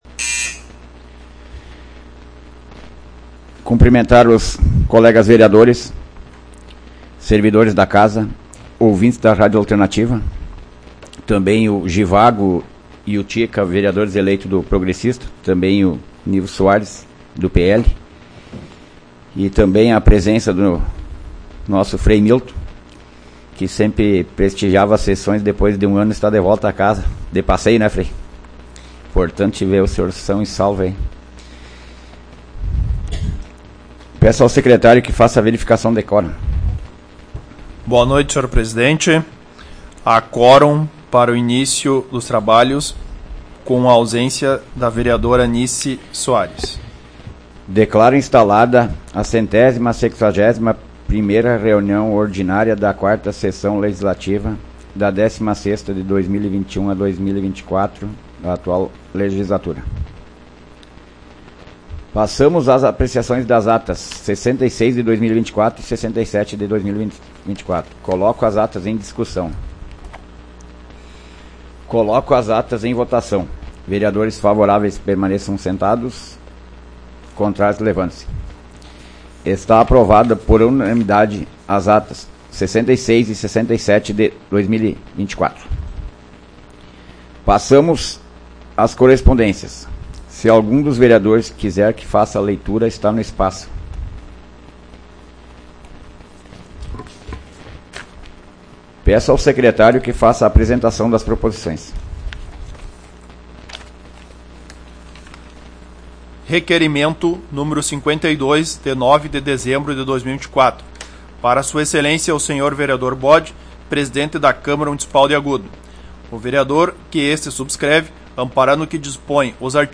Áudio da 161ª Sessão Plenária Ordinária da 16ª Legislatura, de 09 de dezembro de 2024